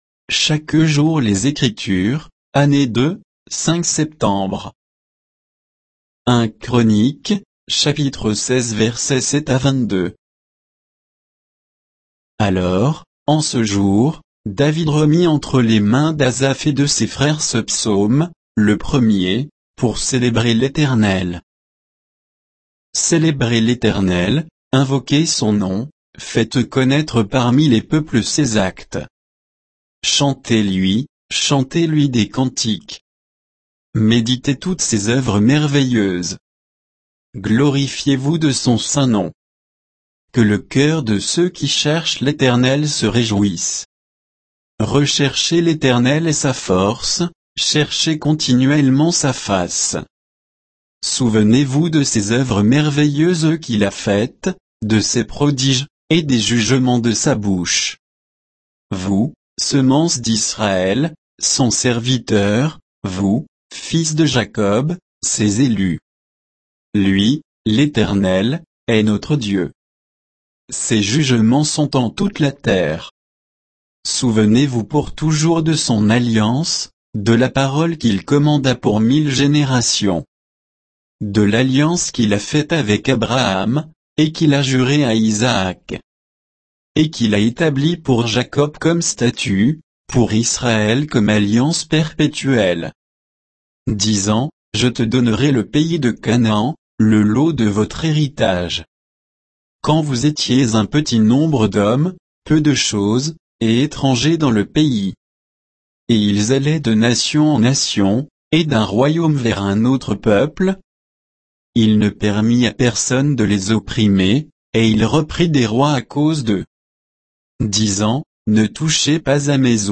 Méditation quoditienne de Chaque jour les Écritures sur 1 Chroniques 16